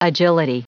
Prononciation du mot agility en anglais (fichier audio)
Prononciation du mot : agility